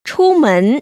[chū//mén] 추먼  ▶